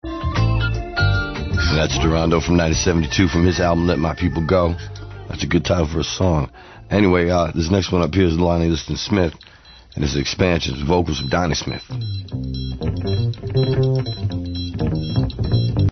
Music cue